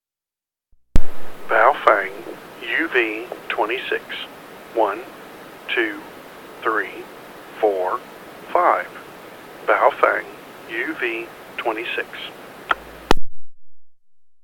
Transmitted audio is pretty clear and clean, with virtually no sibilance unless you get stupid with the mic.
• Good transmitted audio